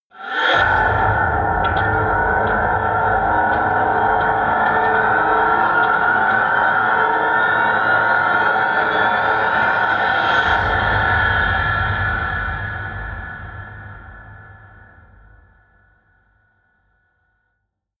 Royalty free sounds: Horror